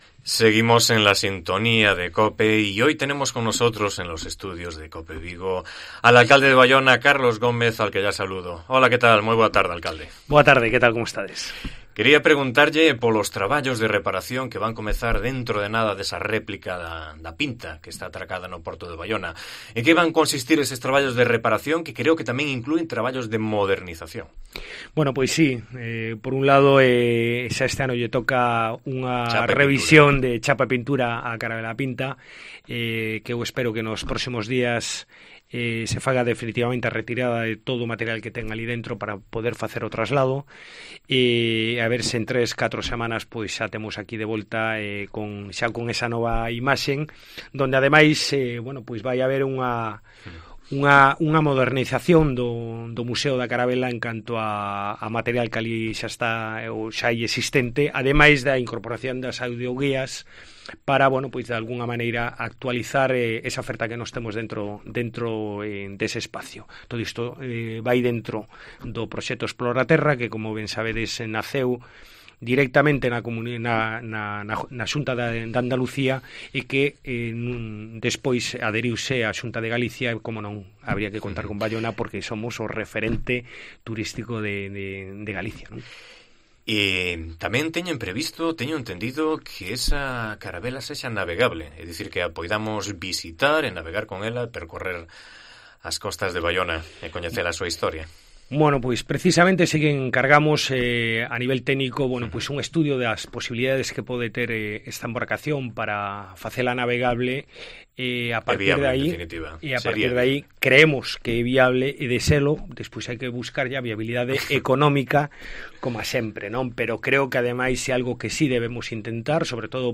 Este mediodía ha estado con nosotros el alcalde de Baiona que nos habló de los trabajos de restauración de la réplica de la carabela atracada en el puerto de Baiona